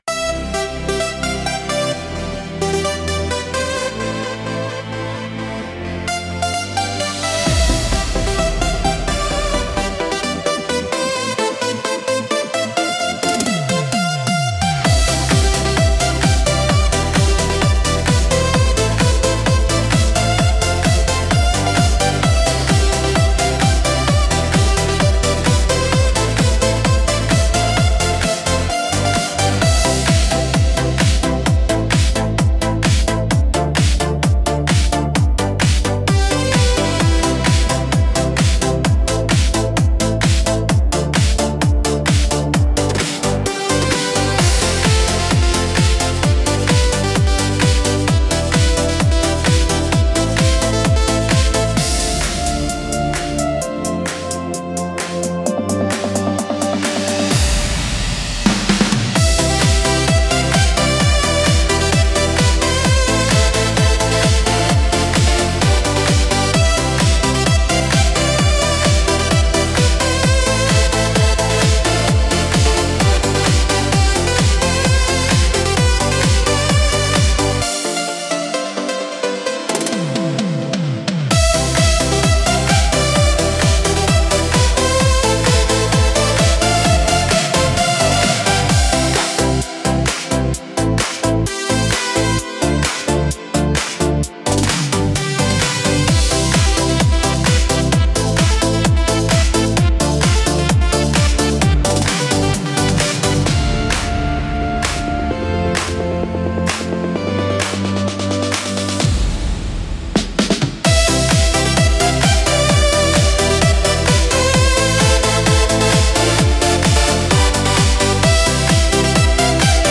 Instrumental - Digital Heartbeat 2.31